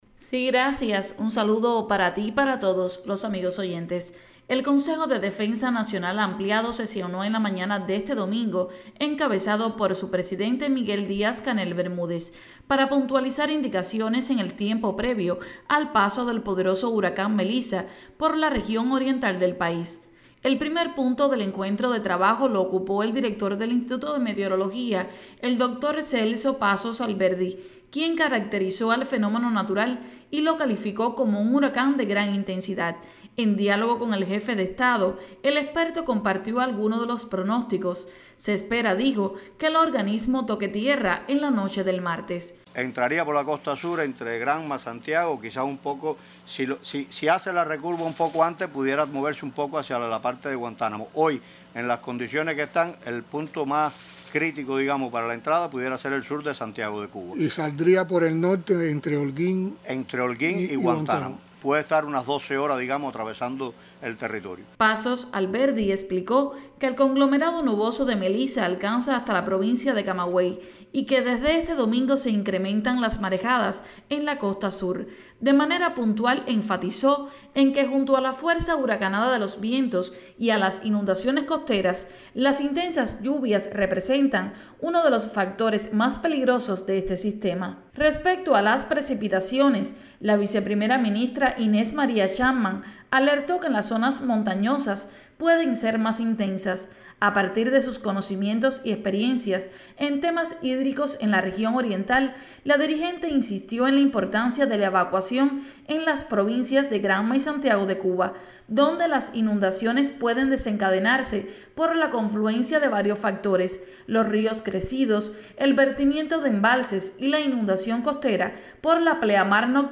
Consejo de Defensa Nacional ampliado sesionó en la mañana de este domingo en videoconferencia con los consejos de defensa provinciales de los territorios que están en fases de Alerta ciclónica e Informativa.